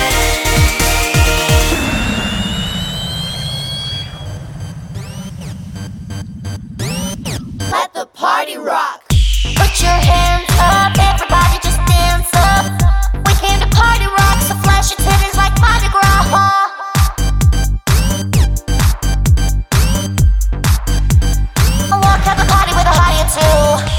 Duet Version R'n'B / Hip Hop 4:22 Buy £1.50